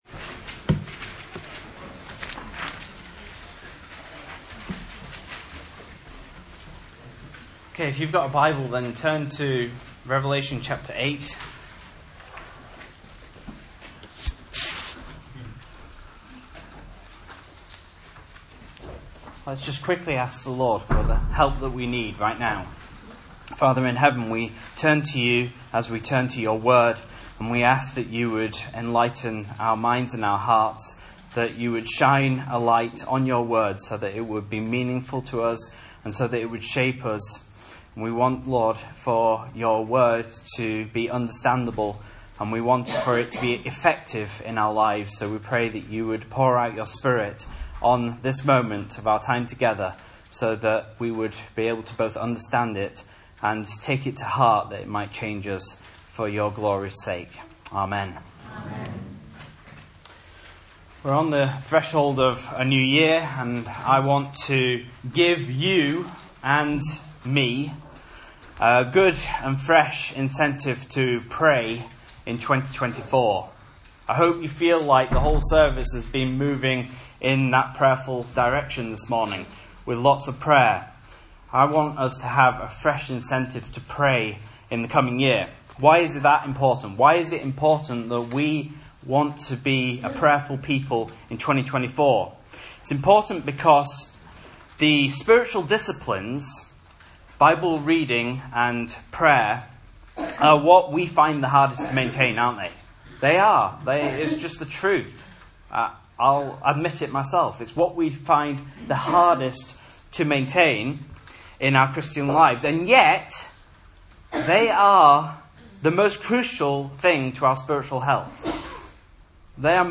Message Scripture: Revelation 8:1-5 | Listen